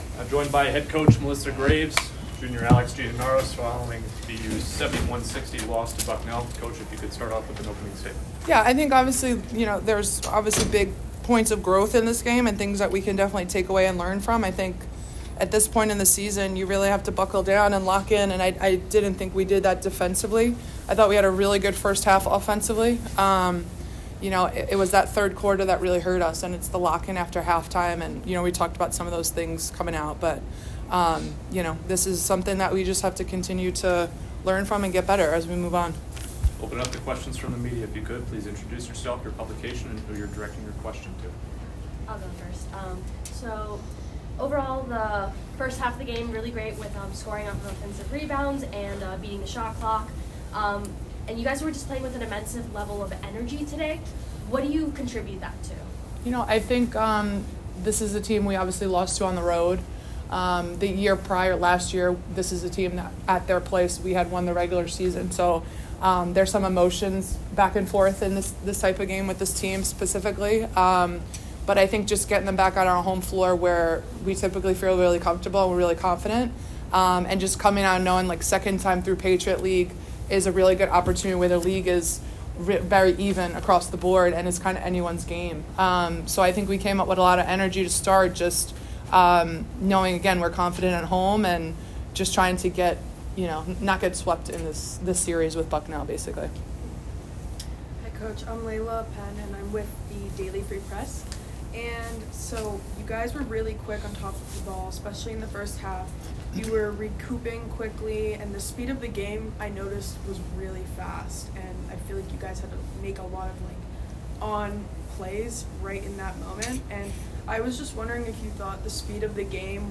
WBB_Bucknell_2_Postgame.mp3